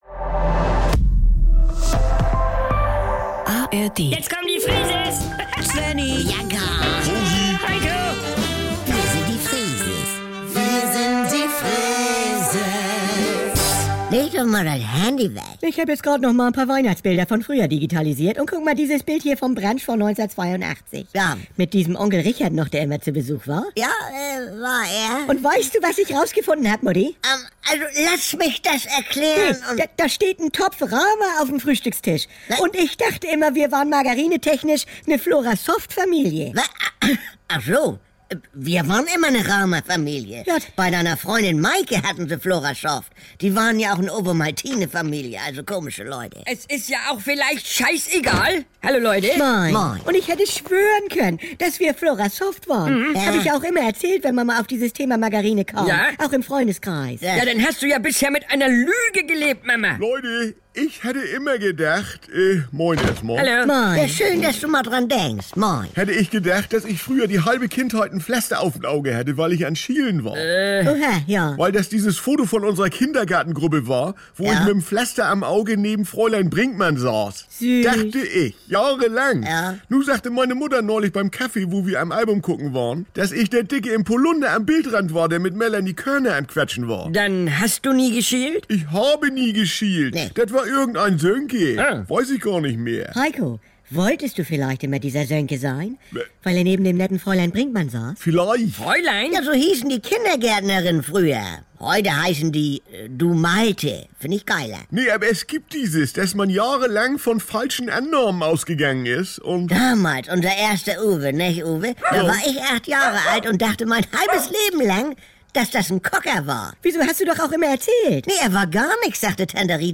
Hier gibt's täglich die aktuelle Freeses-Folge, direkt aus dem Mehrgenerationen-Haushalt der Familie Freese mit der lasziv-zupackenden Oma Rosi, Helikopter-Mama Bianca, dem inselbegabten Svenni sowie Untermieter und Labertasche Heiko. Alltagsbewältigung rustikal-norddeutsch...